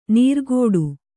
♪ nīrgōḍu